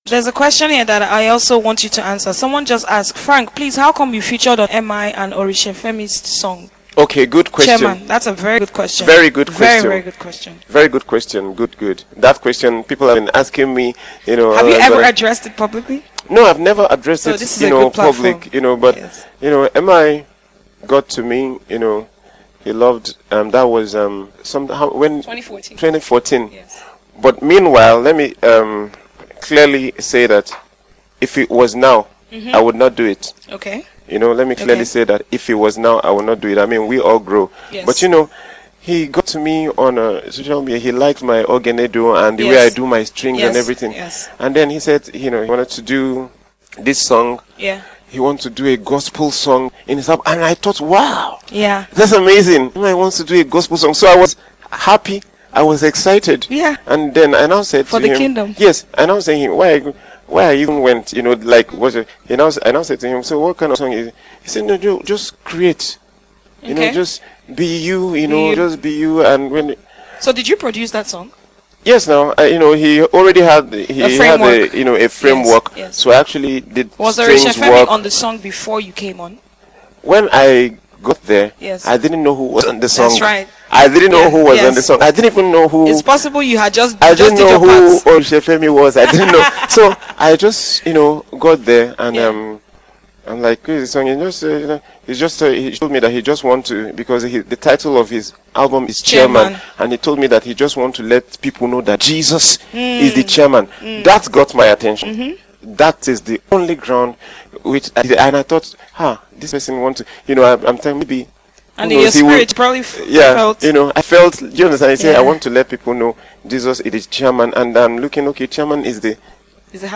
While teaching on Songwriting on the Instagram LIVE session, the multi award winning singer explained how he was approached by the rapper, what he felt about the song’s theme at the time, and his intention at the time.